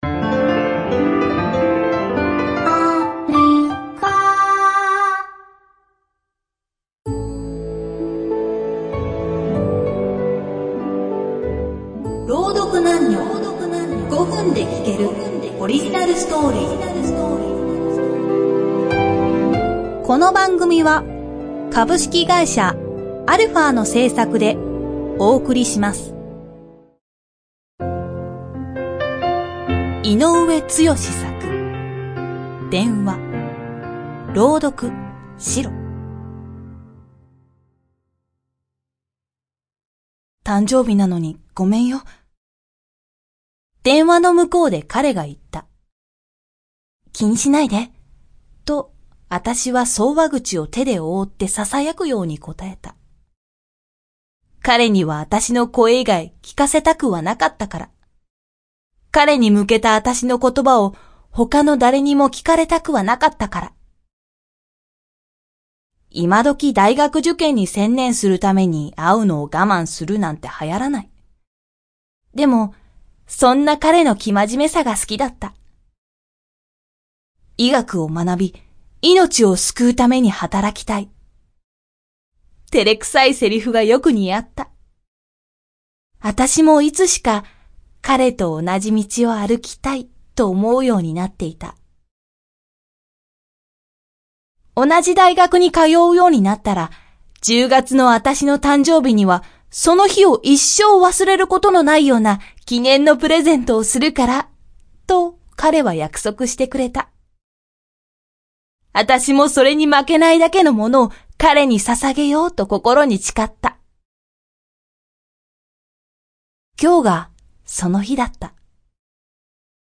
roudoku_32.mp3